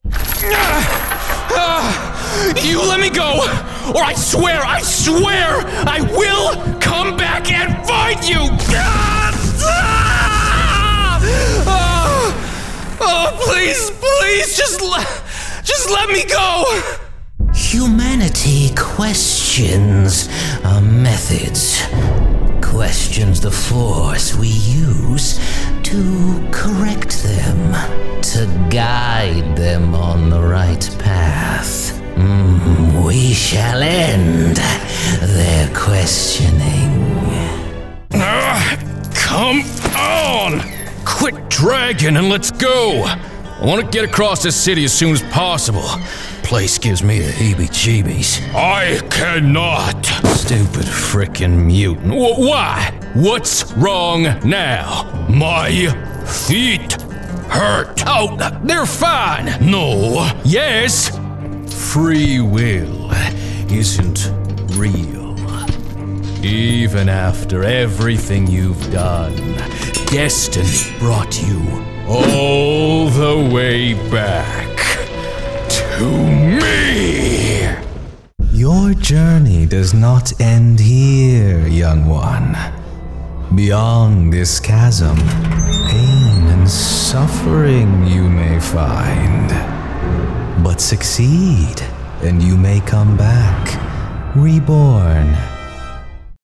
Video Game
Video-Game.mp3